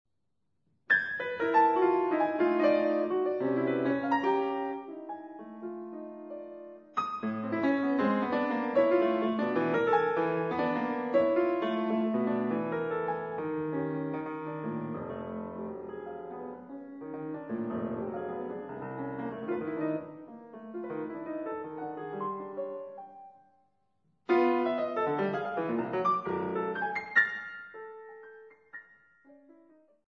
Piano
Holywell Music Room, Oxford